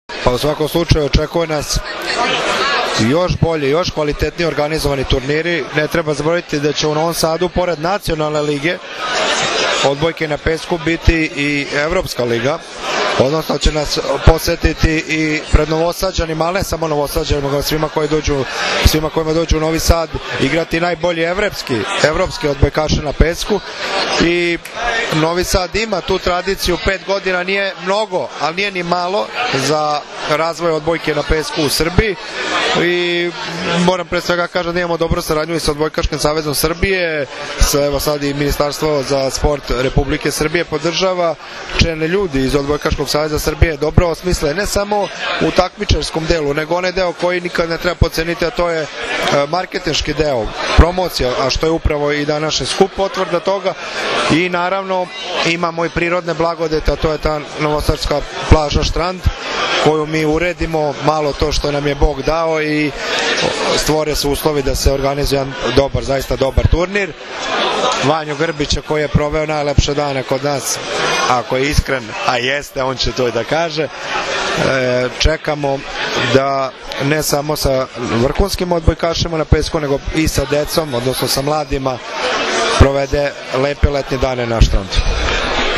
U beogradskom restoranu „Dijagonala” danas je svečano najavljen VI „Vip Beach Masters 2013.“ – Prvenstvo Srbije u odbojci na pesku, kao i Vip Beach Volley liga, u prisustvu uglednih gostiju, predstavnika gradova domaćina, odbojkašica, odbojkaša i predstavnika medija.
IZJAVA MILOŠA VUČEVIĆA, GRADONAČELNIKA NOVOG SADA